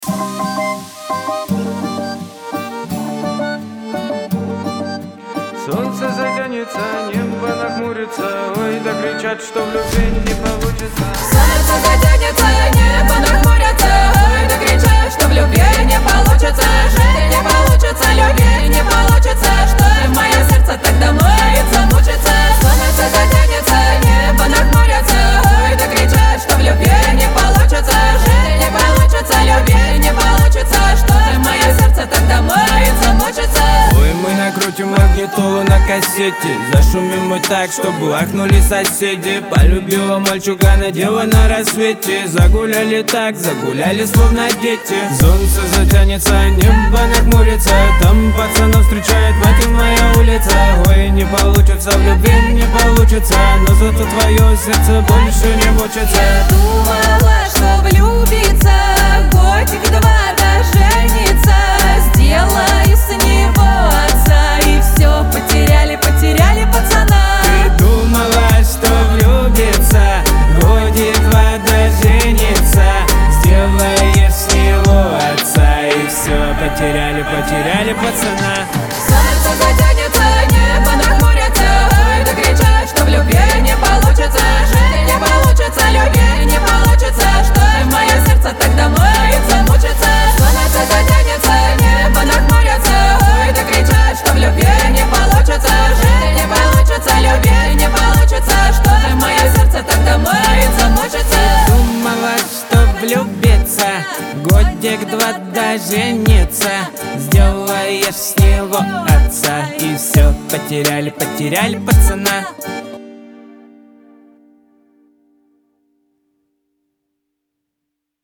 Лирика
pop
дуэт , эстрада